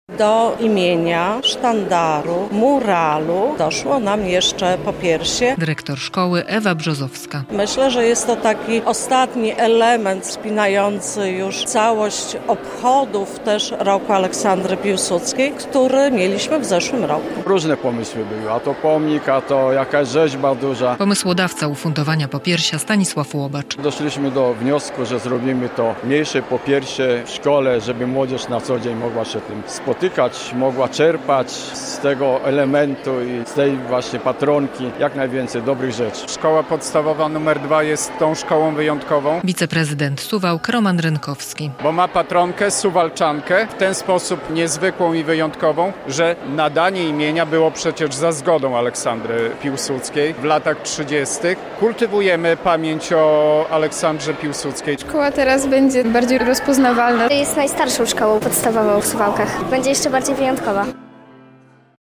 Uroczystość odsłonięcia popiersia Aleksandry Piłsudskiej, patronki szkoły - relacja